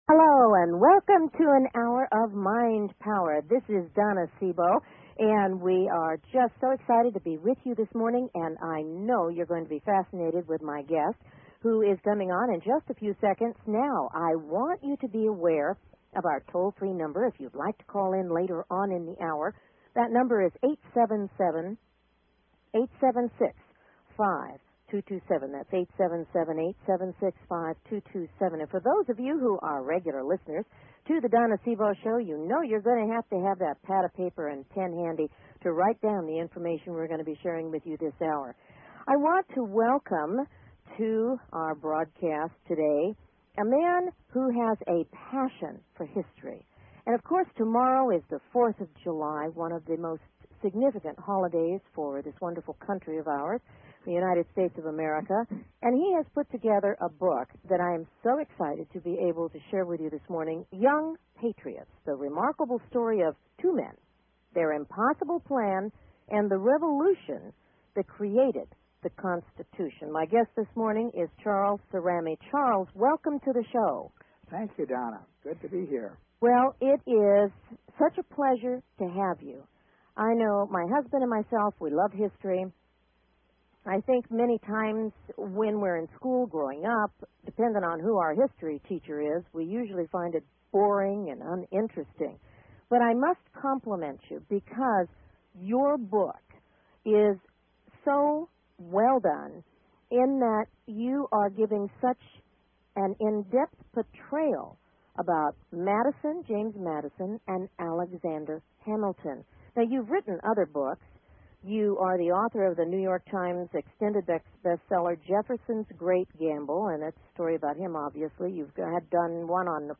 Talk Show Episode, Audio Podcast
Talk Show